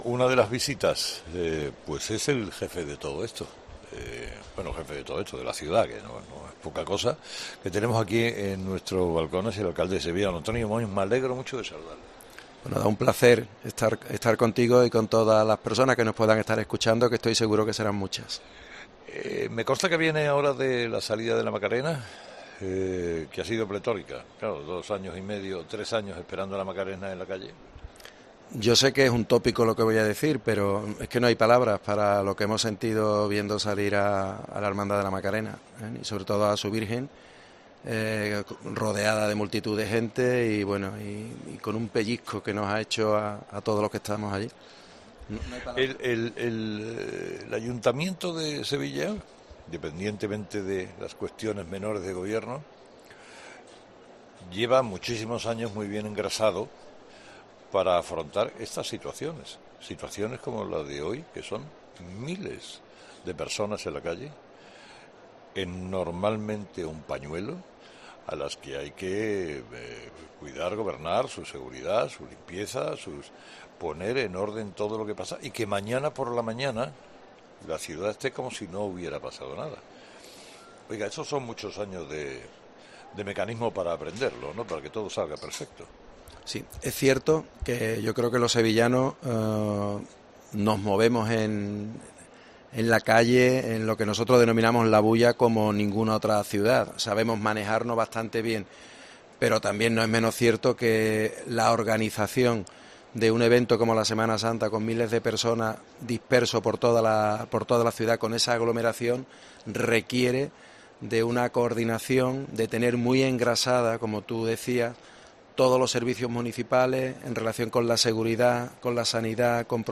Carlos Herrera ha tenido la oportunidad de entrevistar, durante el especial de ocho horas que ha dirigido desde 'La Madrugá' de Sevilla, al alcalde de Sevilla, Antonio Muñoz, que ha explicado en COPE cómo se organiza una ciudad como Sevilla para vivir, la que es una de las noches más especiales del año para todos los sevillanos.